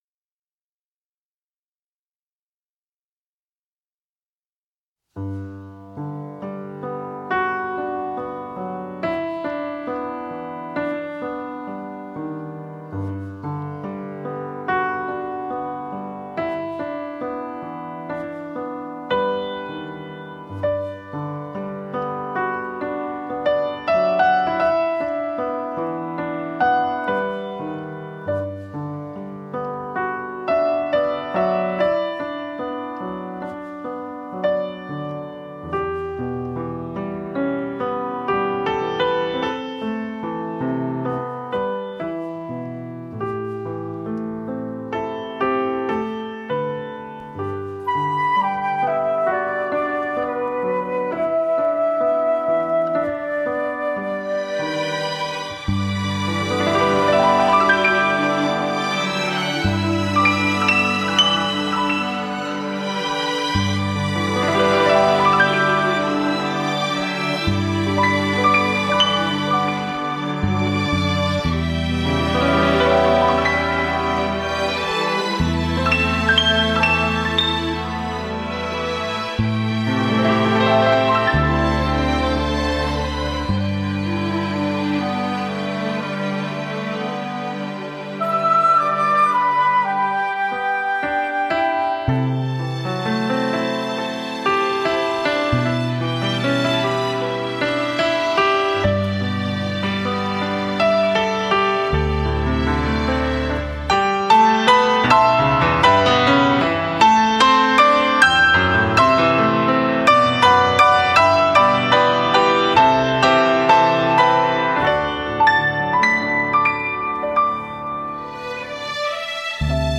中国钢琴浪漫作品精华集
15首改编自最具民族风情之民歌的钢琴小品
全新数码音频处理